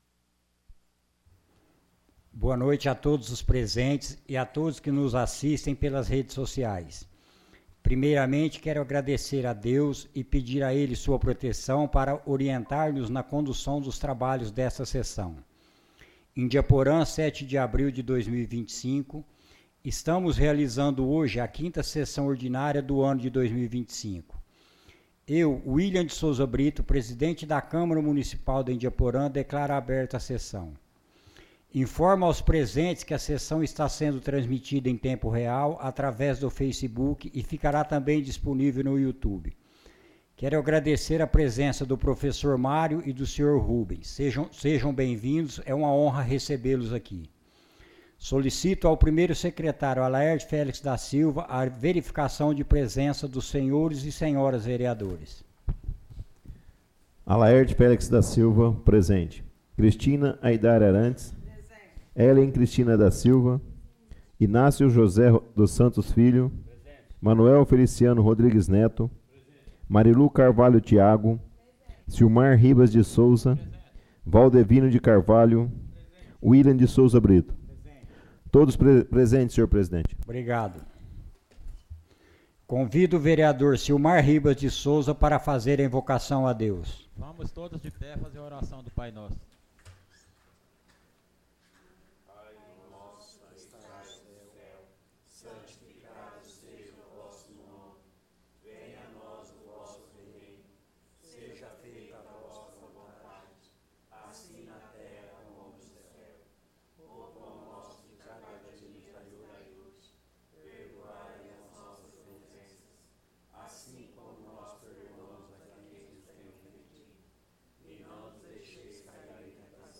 Informou aos presentes que a sessão estava sendo transmitida em tempo real através do Facebook e ficará também disponível no Youtube.